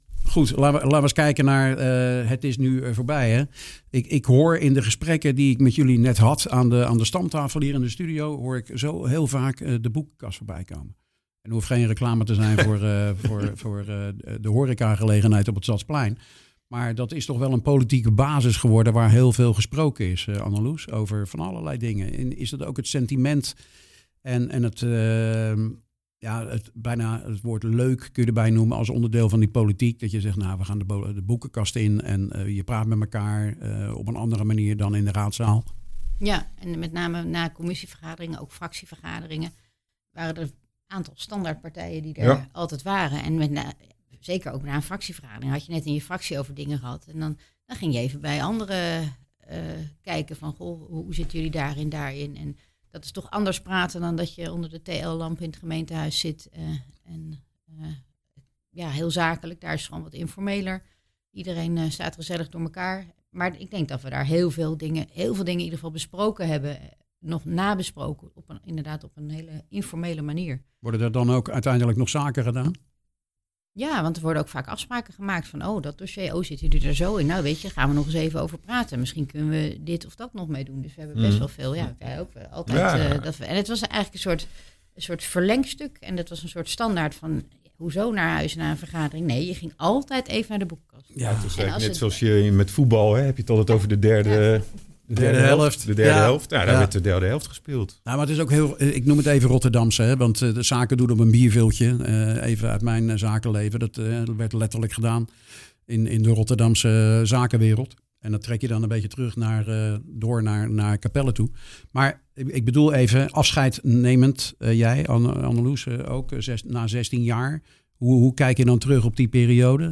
De afgelopen twee weken hebben we nieuwe raadsleden in de studio gehad, vandaag twee ex-raadsleden die bij elkaar 28 jaar in de raad hebben gezeten. Annelous van der Klauw-Cornet bij D66 en Bas van Loef bij Capels Bewoners Belang.